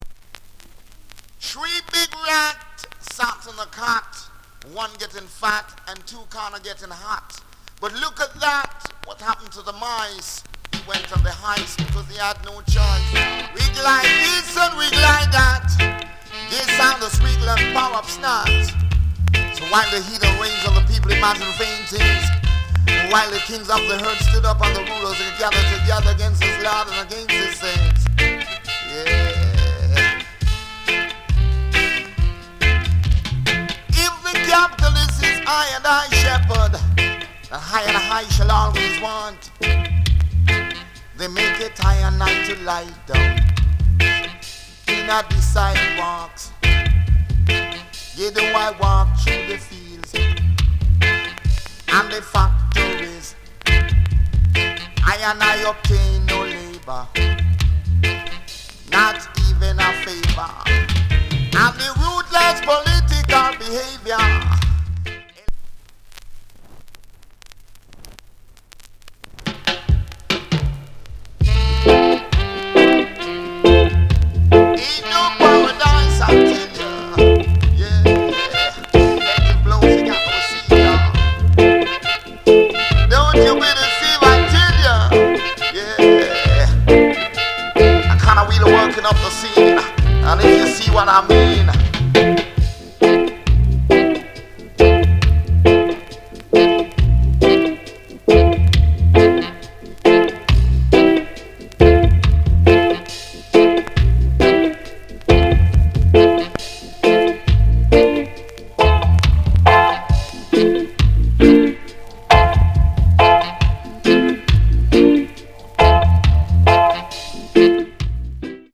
** Split Stereo